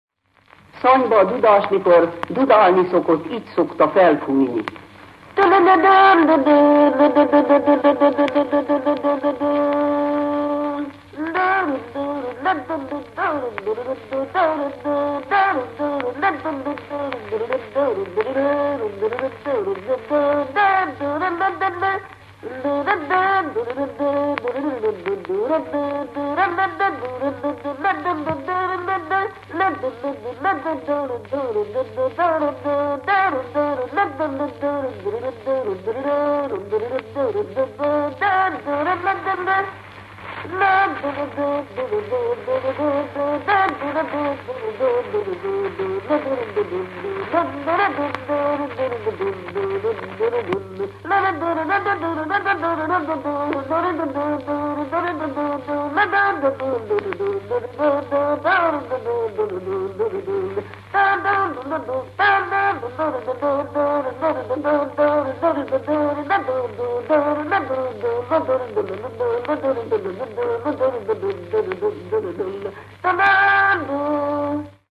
Publikált népzenei felvételek -- On-line adatbázis
Műfaj Dudautánzás
Helység Szany